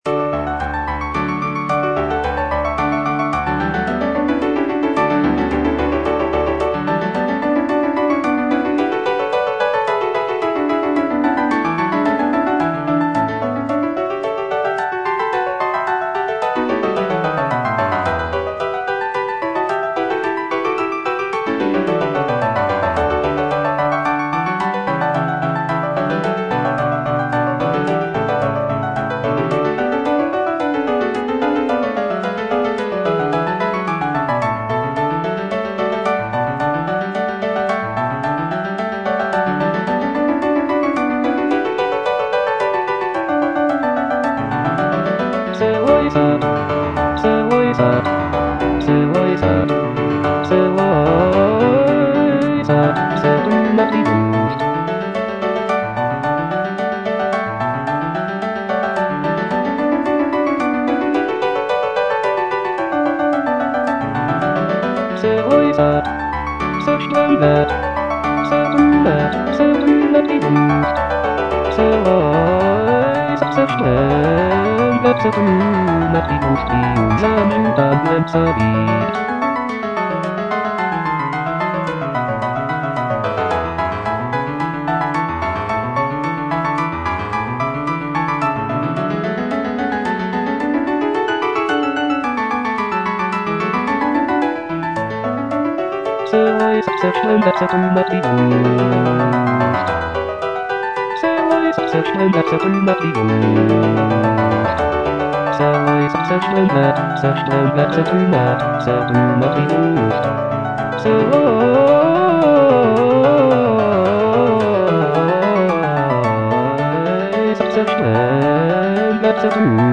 CANTATA
Bass (Voice with metronome) Ads stop